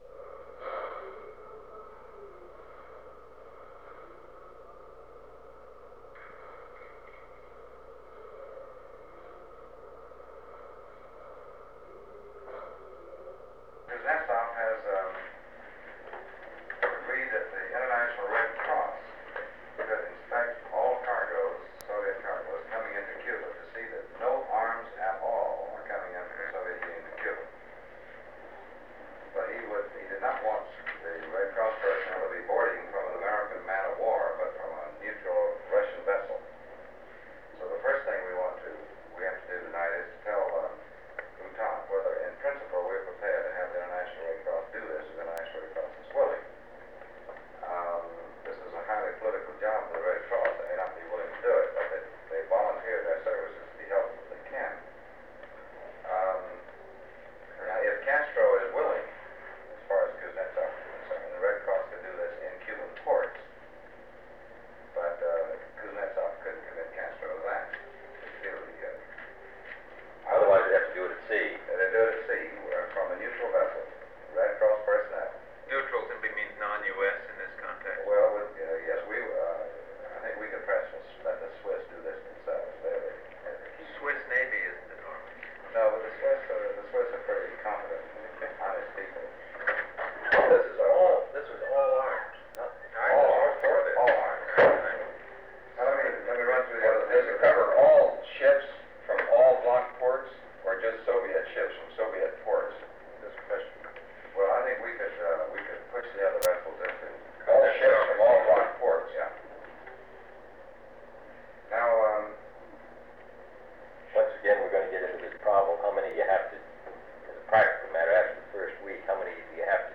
Executive Committee Meeting of the National Security Council
Secret White House Tapes | John F. Kennedy Presidency Executive Committee Meeting of the National Security Council Rewind 10 seconds Play/Pause Fast-forward 10 seconds 0:00 Download audio Previous Meetings: Tape 121/A57.